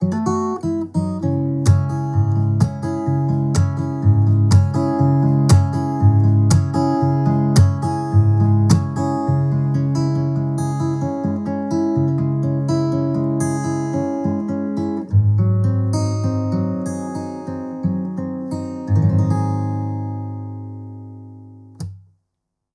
Próbki dzwiękowe Audio Technica AE-3000
Audio Technica AE3000 mikrofon - gitara akustyczna